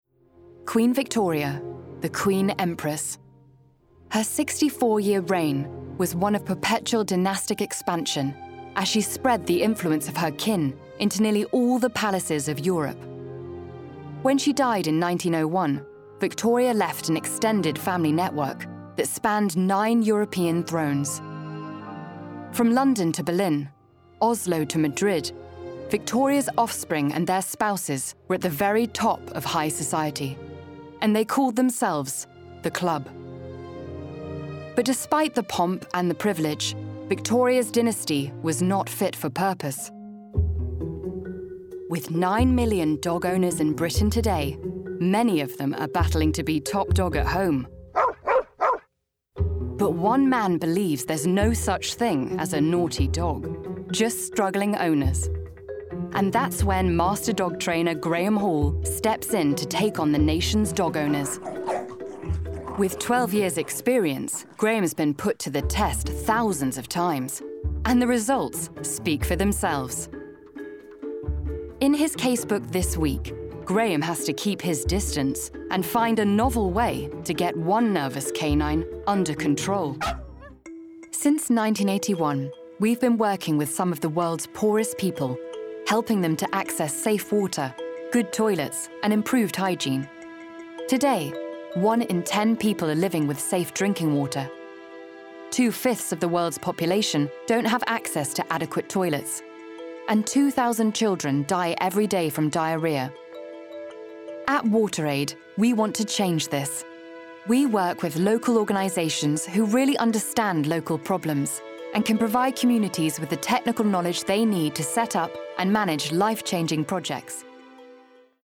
Narration Reel
• Native Accent: RP
• Home Studio
She speaks with a cool, confident authority that would be perfect for commercial and corporate projects.